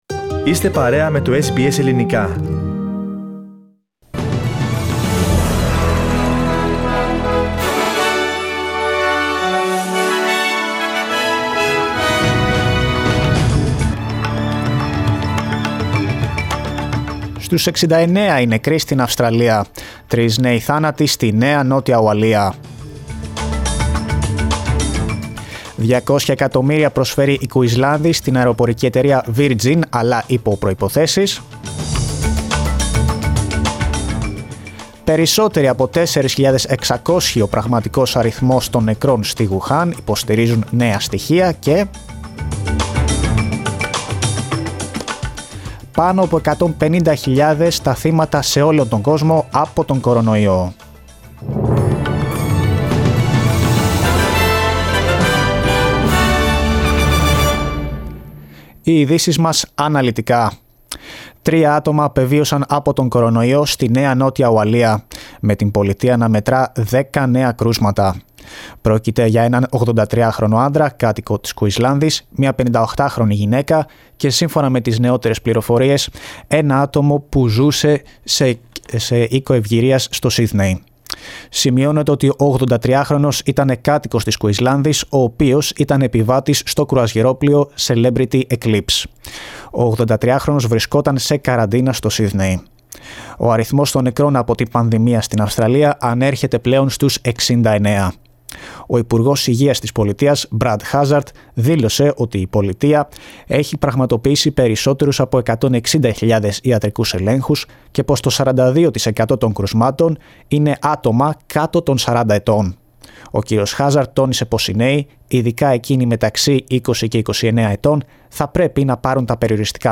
Δελτίο Ειδήσεων Σάββατο 18.04.20